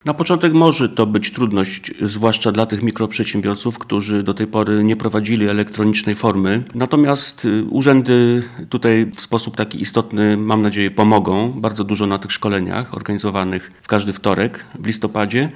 – mówi wiceminister finansów i zastępca szefa krajowej administracji skarbowej Paweł Cybulski.